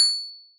SingleDing
bell chime cute ding ring short sound effect free sound royalty free Sound Effects